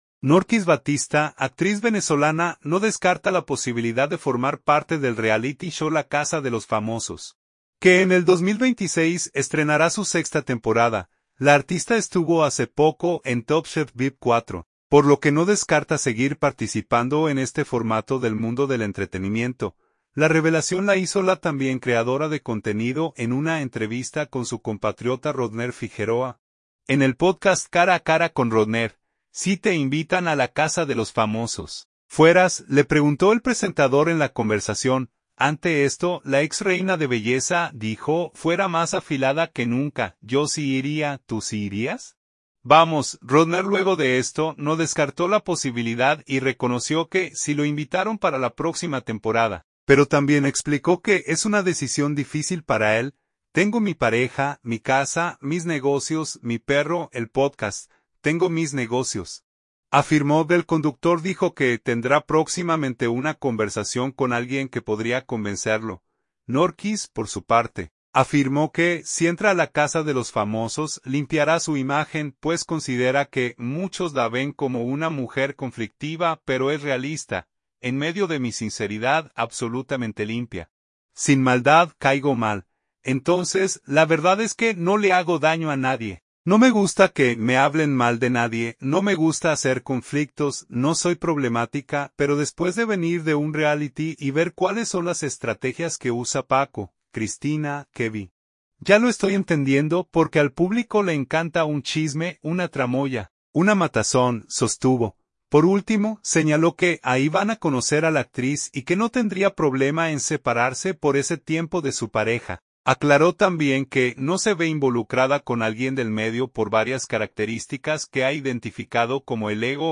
La revelación la hizo la también creadora de contenido en una entrevista con su compatriota Rodner Figueroa, en el podcast Cara a cara con Rodner.